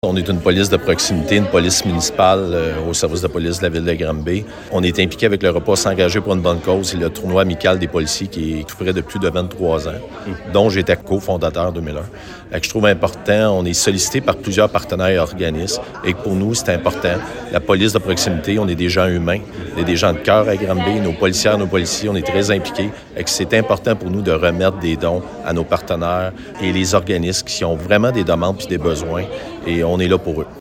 Le directeur du Service de police de Granby, Bruno Grondin.